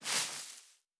Tall_Grass_Mono_03.wav